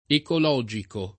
ecologico [ ekol 0J iko ]